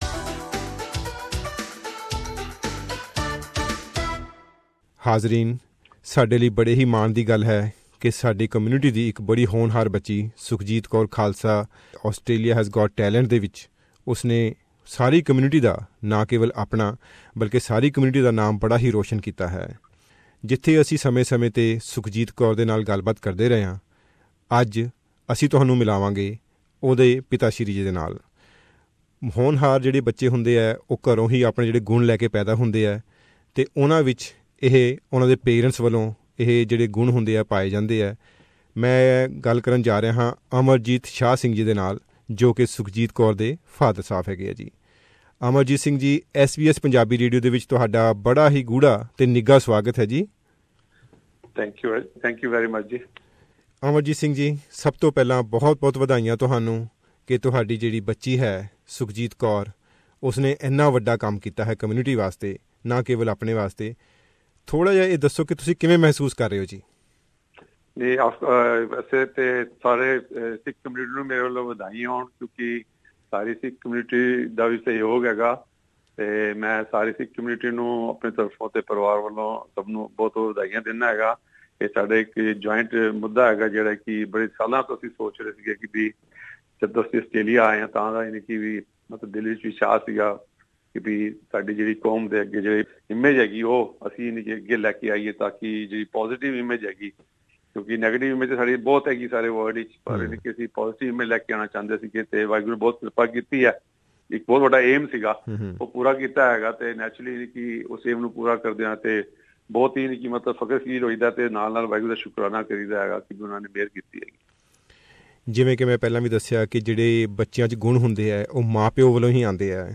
In the first interview